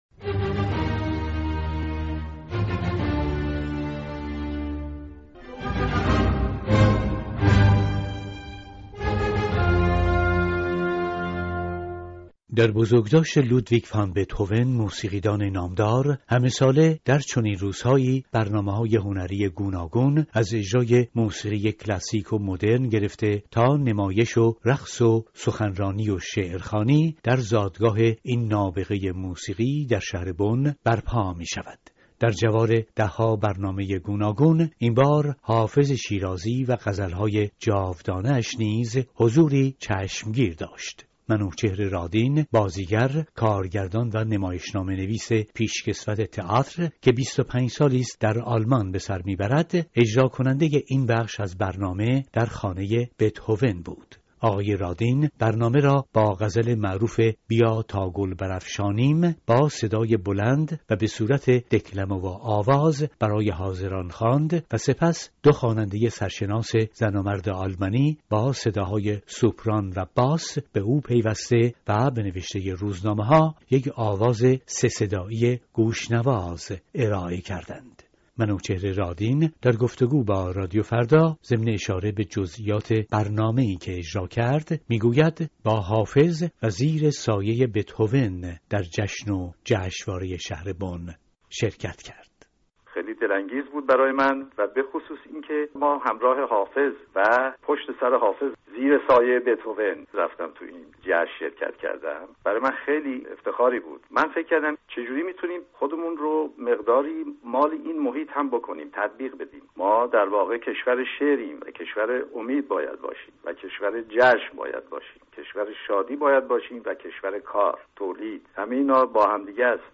از بزرگداشت لودویک فون بتهوون گزارش می‌دهد